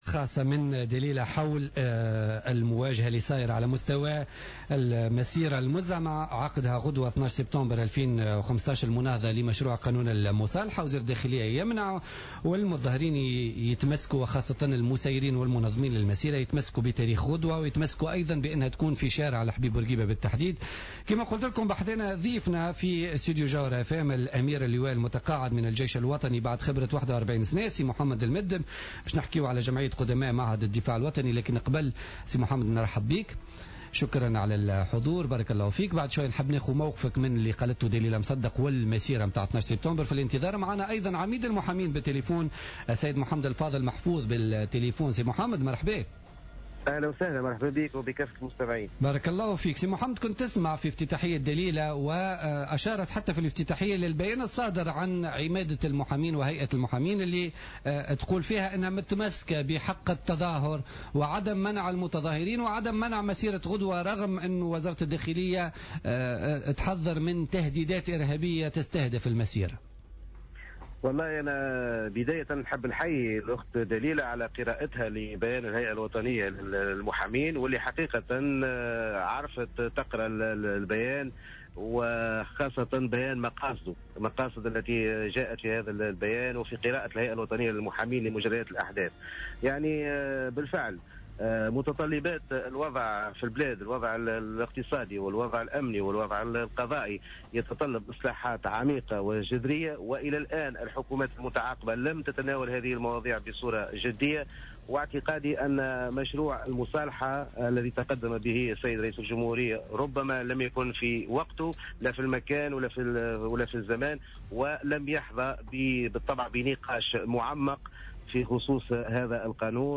أكد عميد المحامين محمد الفاضل محفوظ في مداخلة له في بوليتيكا اليوم الجمعة 11 سبتمبر 2015 أن الوضع الاقتصادي والأمني والقضائي في بالبلاد يتطلب اصلاحات عميقة وجذرية لم تتناولها الحكومات المتعاقبة على تونس بالجدية المطلوبة.